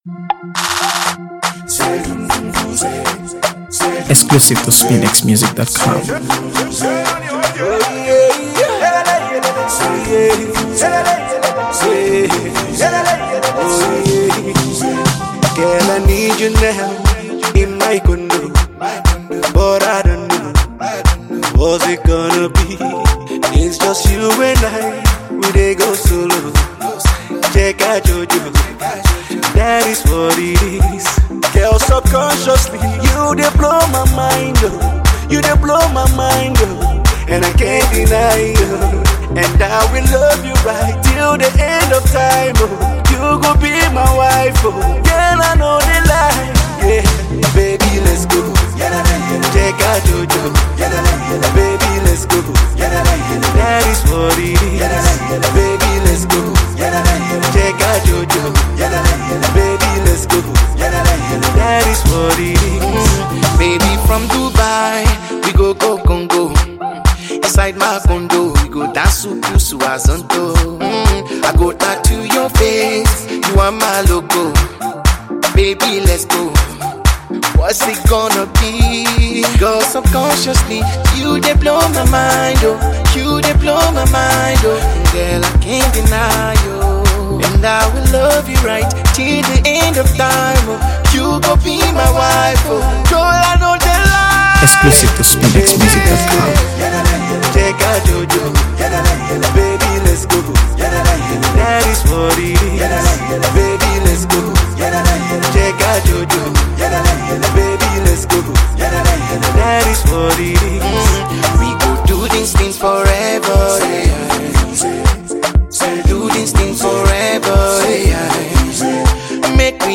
party-ready number
sensational singer
love song